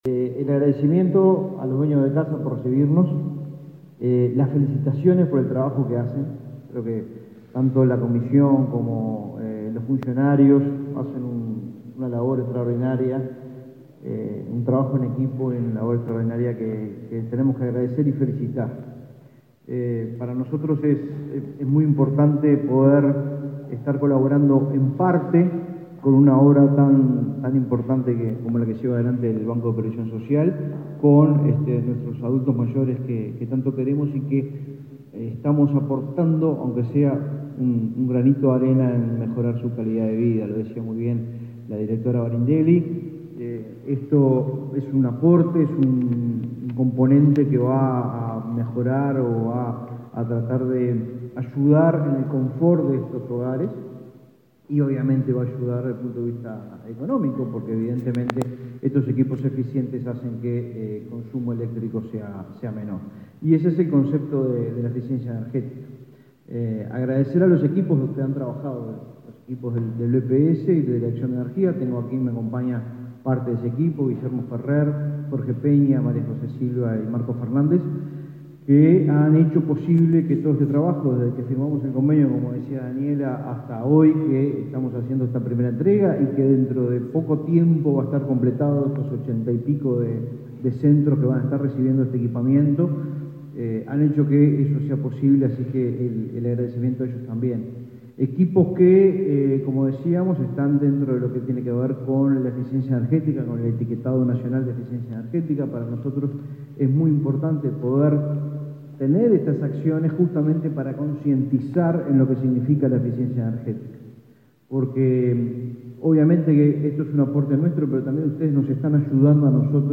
Palabras de autoridades del BPS y el MIEM
Palabras de autoridades del BPS y el MIEM 16/06/2023 Compartir Facebook X Copiar enlace WhatsApp LinkedIn Este viernes 16, el Banco de Previsión Social (BPS) y el Ministerio de Industria, Energía y Minería (MIEM) entregaron equipos eléctricos eficientes a un hogar de ancianos. El director nacional de Energía, Fitgerald Cantero, y el presidente del organismo previsional, Alfredo Cabrera, destacaron la importancia de la donación.